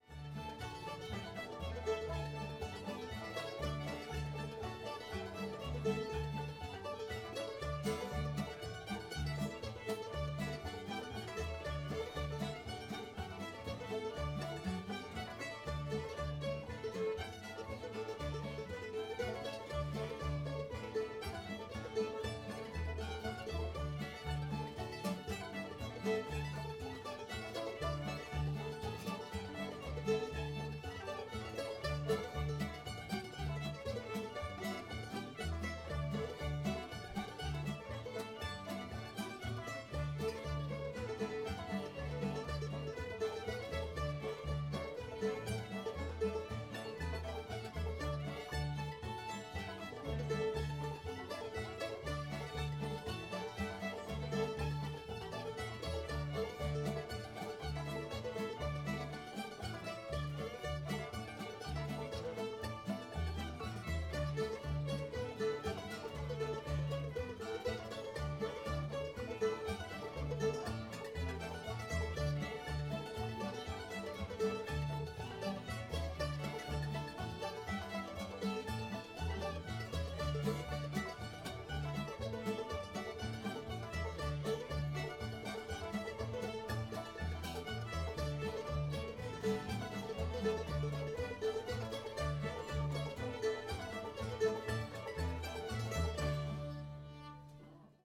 polly put the kettle on [G]